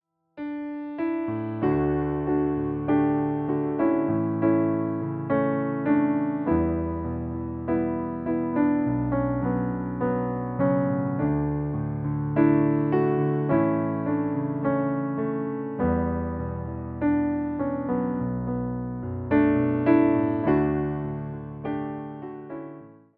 Głęboka i nastrojowa aranżacja muzyczna
Wersja demonstracyjna:
53 BPM
D – dur